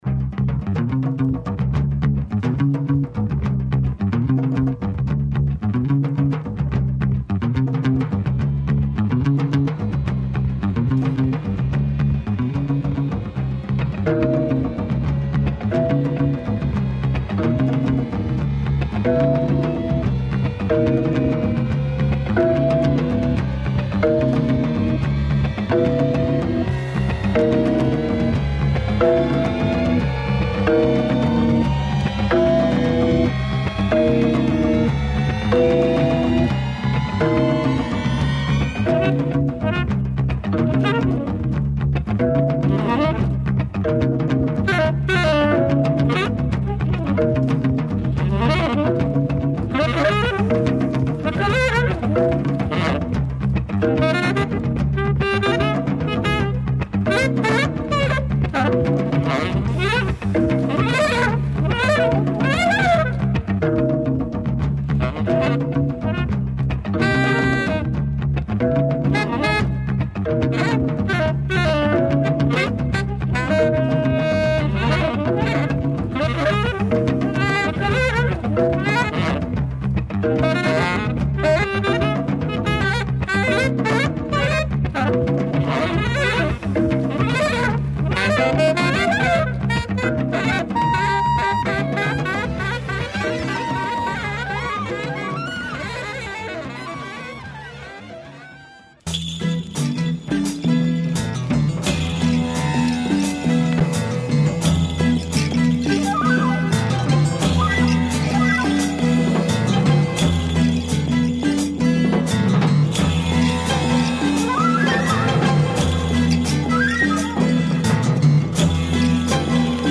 with its devastating organ groove
with a funky / prog pop / sitar psych feel